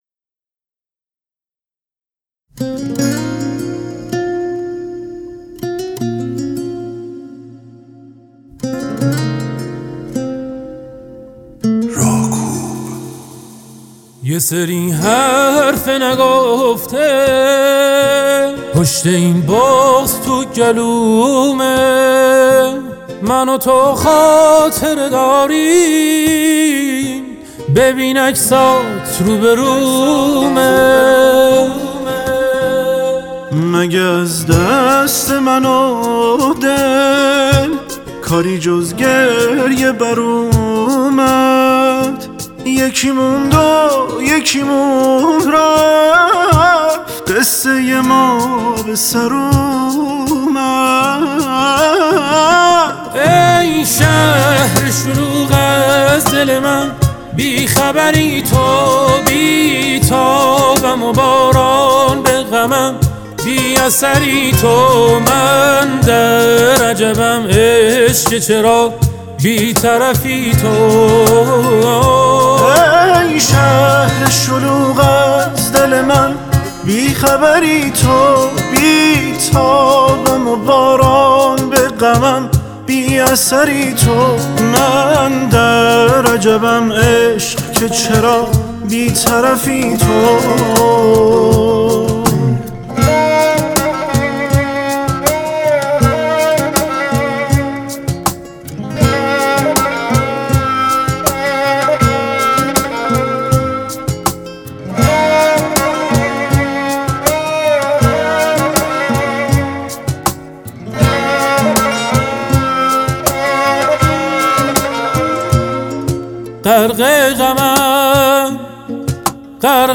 آهنگ احساسی و پرطرفدار
با صدای گرم و پر احساسش